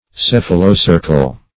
Search Result for " cephalocercal" : The Collaborative International Dictionary of English v.0.48: Cephalocercal \Ceph`a*lo*cer"cal\, a. [Cephalo- + Gr. ke`rkos tail.]